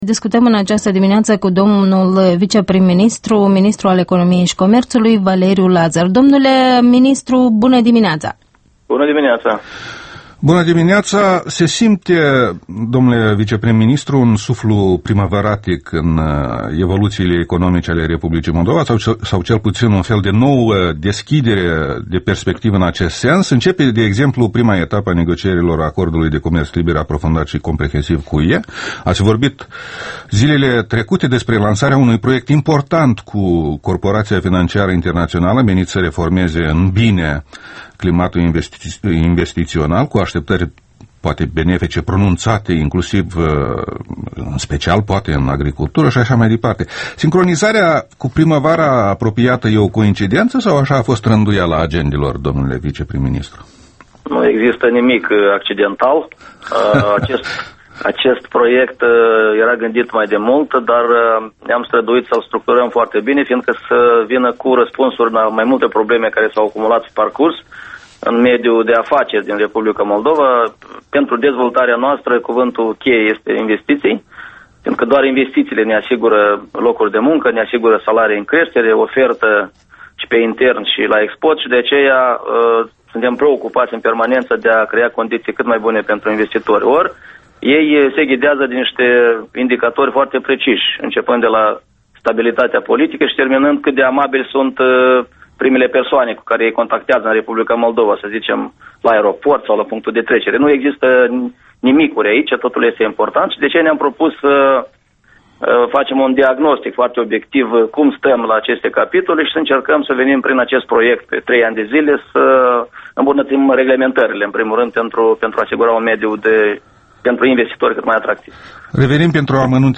Interviul dimineții la Europa Liberă: cu vicepremierul Valeriu Lazăr